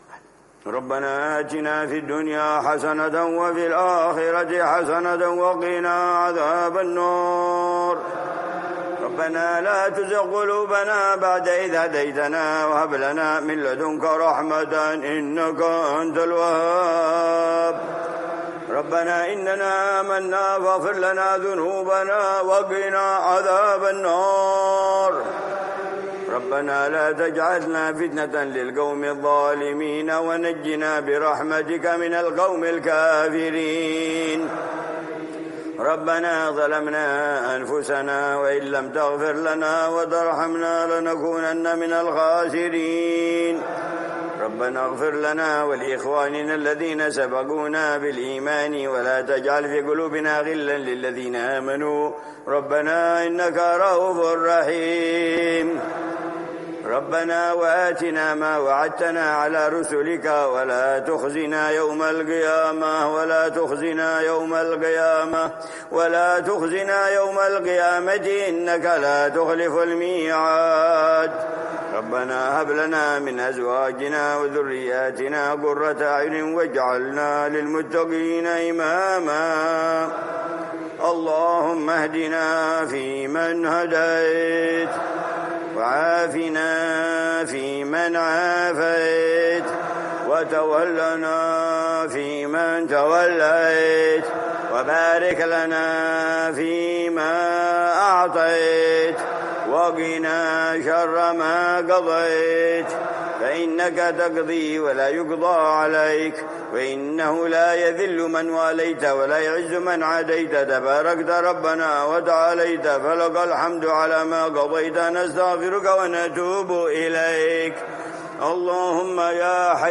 دعاء ليلة 17 رمضان 1446 - الإيمان
في قنوت الوتر، ليلة الإثنين 17 رمضان 1446هـ ( الإيمان )